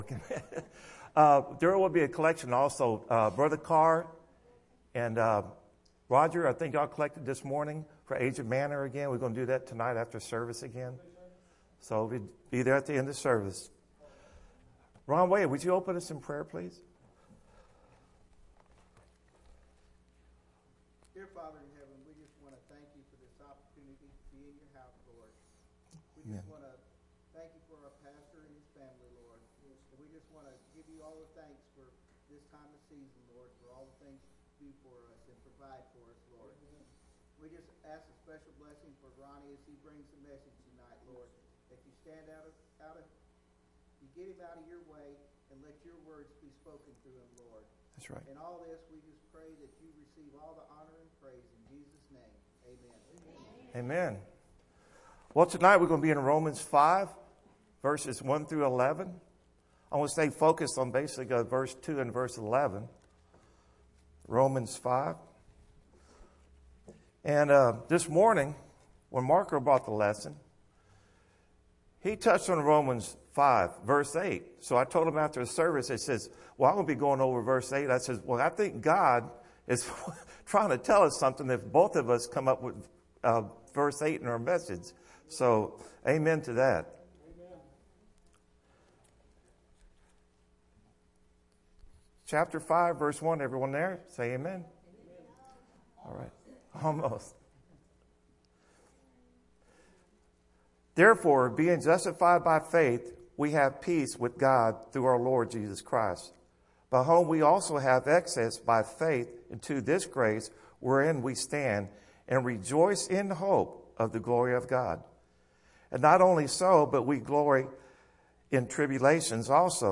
Nov 29 PM - New Hope Baptist Church